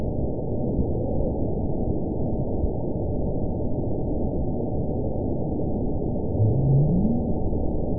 event 917856 date 04/19/23 time 03:06:18 GMT (2 years ago) score 9.54 location TSS-AB05 detected by nrw target species NRW annotations +NRW Spectrogram: Frequency (kHz) vs. Time (s) audio not available .wav